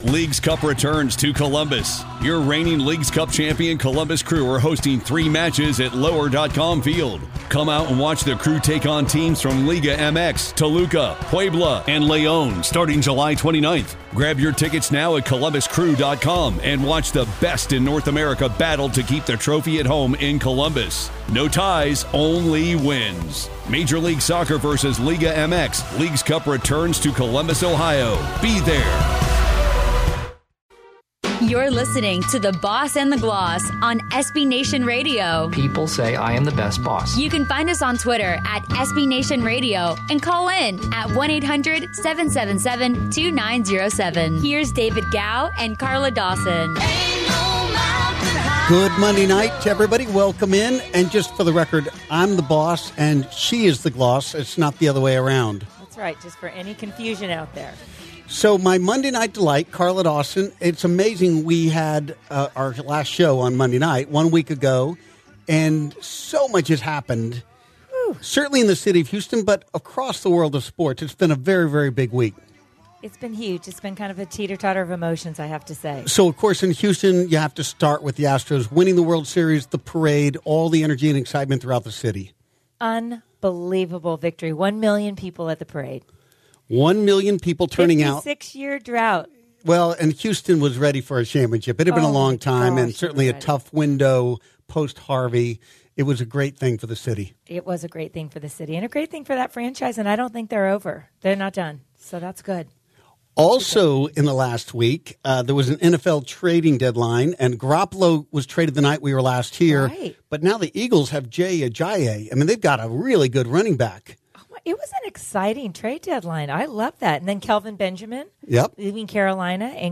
Special Guest.. Former cowboys runningback and head coach for the Cowboys, Broncos, Giants and Atlanta Falcons DAN REEVES. Around the League on the NFL. Check in on the Packers and Lions Game.